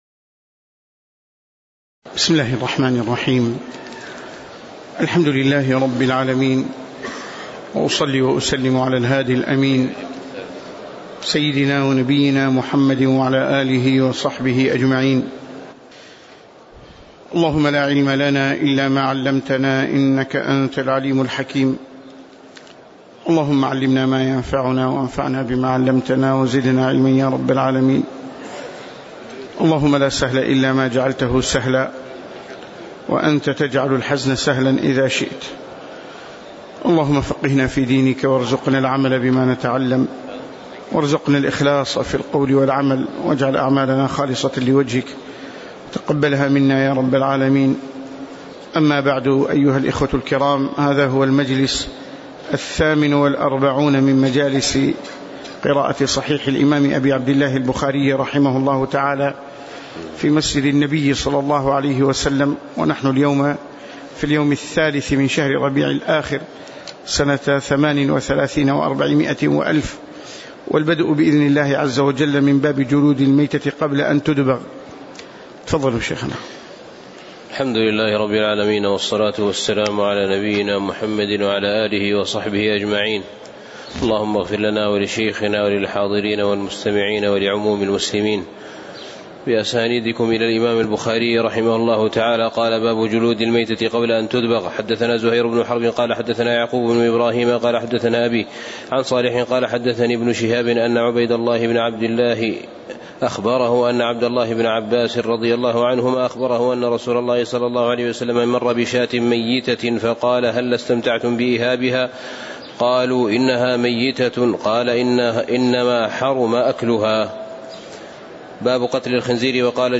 تاريخ النشر ٣ ربيع الثاني ١٤٣٨ هـ المكان: المسجد النبوي الشيخ